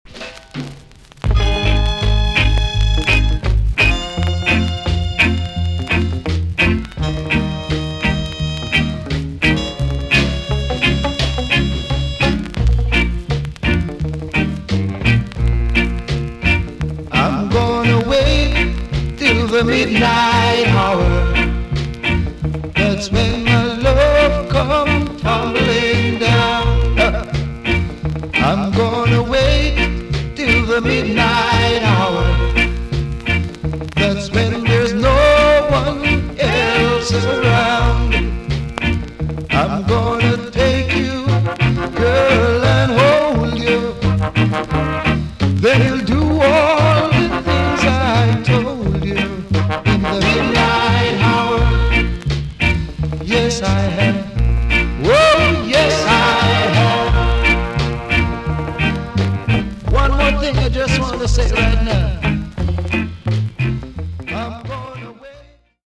TOP >SKA & ROCKSTEADY
VG+~VG ok 軽いチリノイズが入ります。
ROCK STEADY